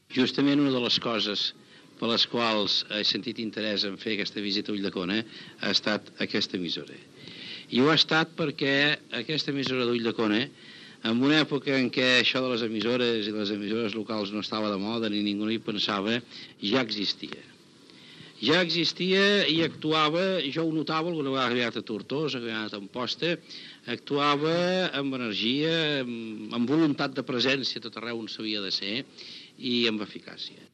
Paraules del president de la Generalitat Jordi Pujol el dia de la inauguració dels nous estudis
Extret del programa "La ràdio a Catalunya" emès per Ràdio 4, l'any 1986.